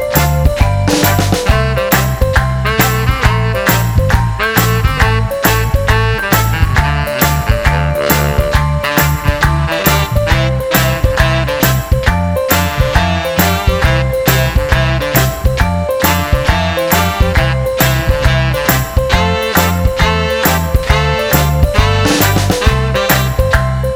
No Backing Vocals Soul / Motown 2:46 Buy £1.50